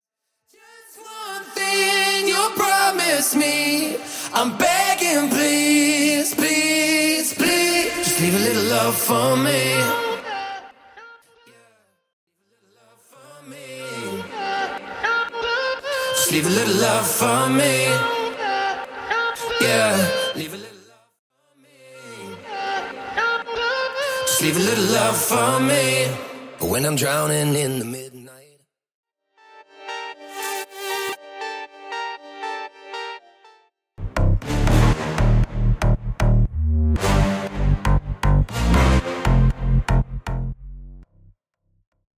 Studio Bassline Loop Stem
Studio Composite Drum Loop
Studio Double Synth Part
Studio Looped Acapella